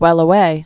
(wĕlə-wā) Archaic